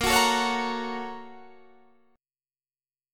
BbmM7#5 chord